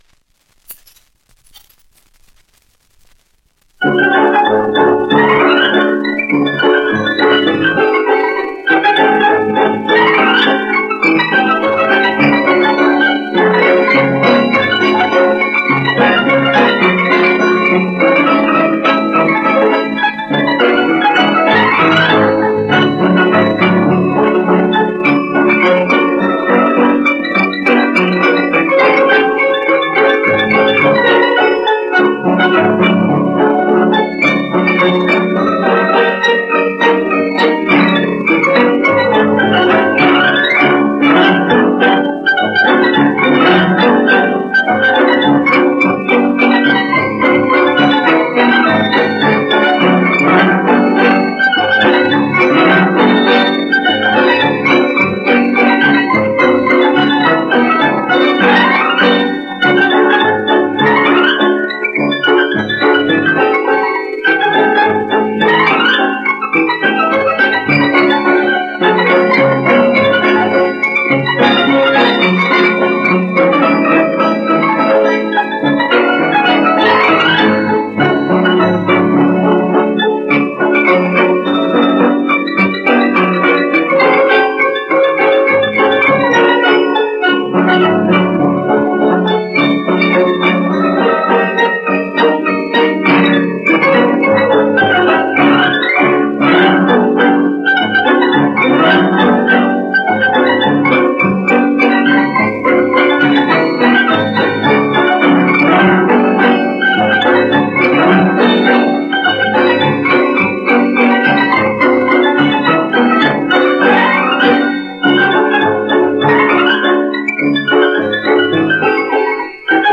El pobre Valbuena. Polca japonesa (sonido mejorado)